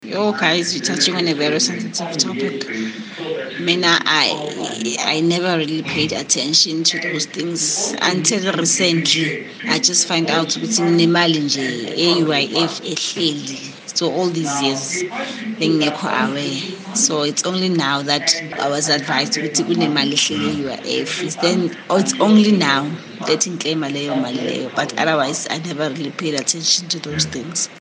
Here are some of the views from Kaya 959 listeners: